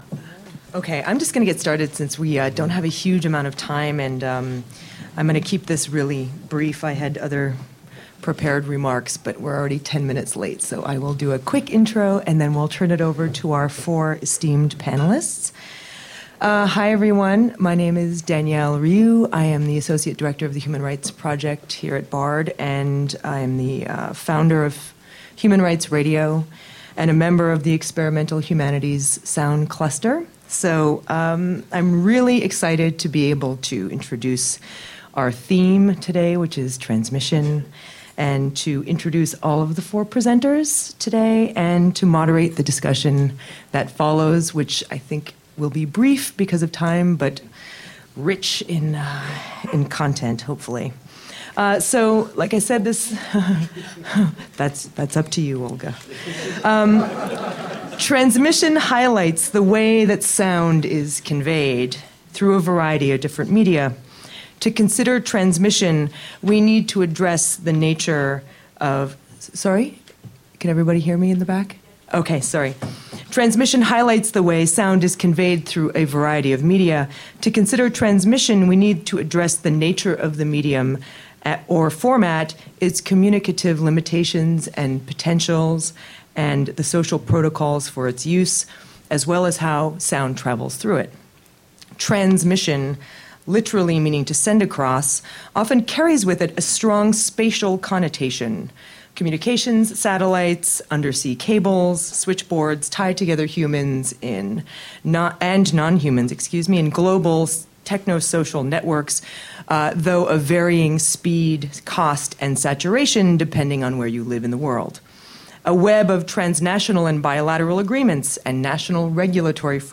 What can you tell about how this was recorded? Transmission Panel at the Bard Sound Symposium (Audio)